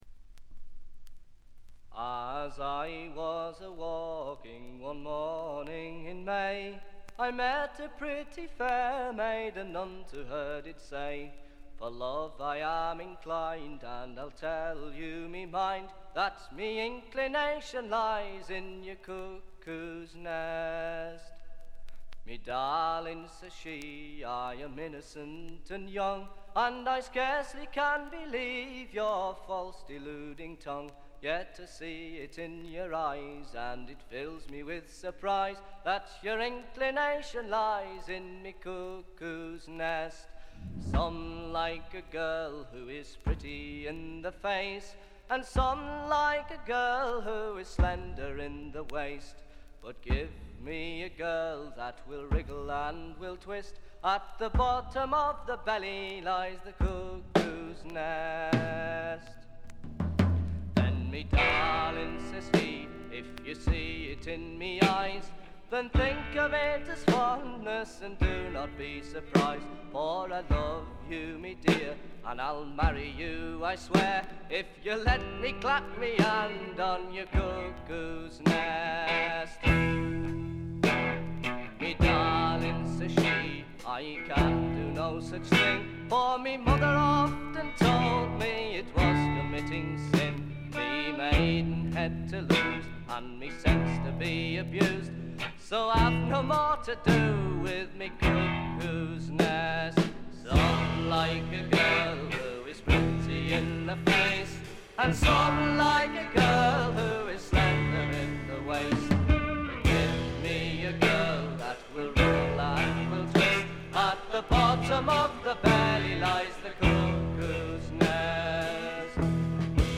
静音部の軽微なチリプチ程度。
エレクトリック・トラッド基本中の基本！
試聴曲は現品からの取り込み音源です。